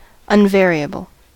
unvariable: Wikimedia Commons US English Pronunciations
En-us-unvariable.WAV